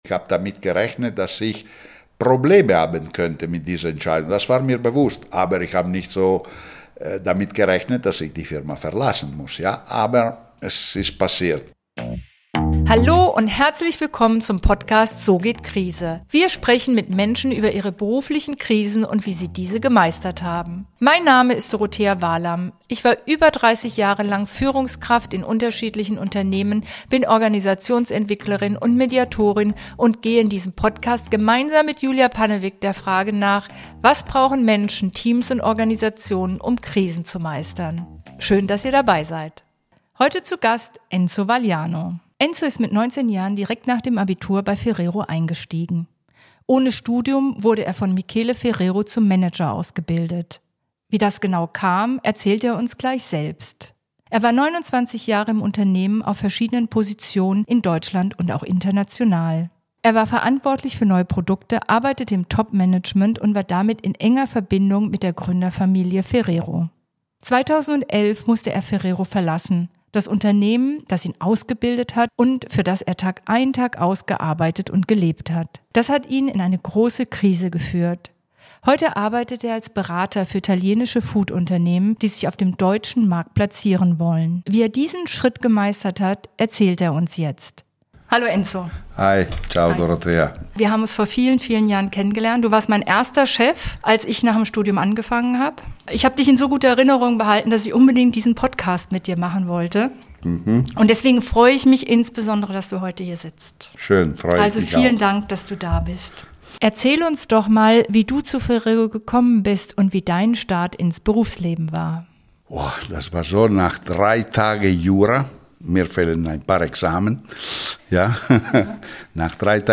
Ein ehrliches Gespräch über Veränderungen, berufliche Wendepunkte und den Mut, neu anzufangen.